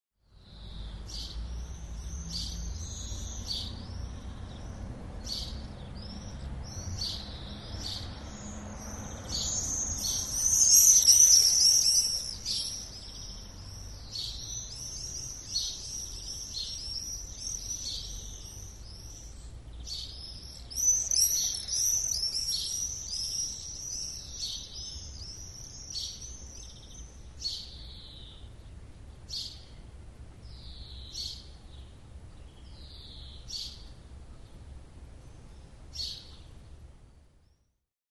Вы услышите их щебет, трели и другие голосовые реакции, которые помогут вам ближе познакомиться с этими удивительными птицами.
Городская атмосфера перед дождем с ласточками